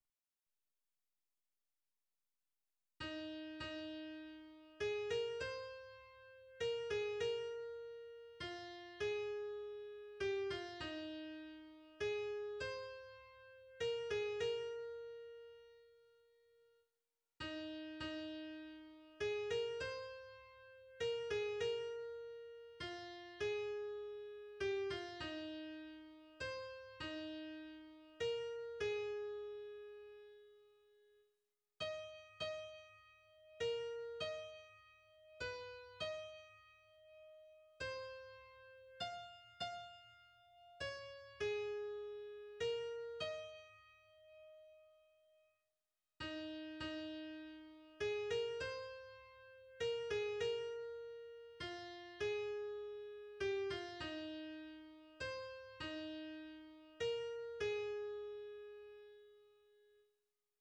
a-a'-b-aの二部形式からなる。終始8分の6拍子。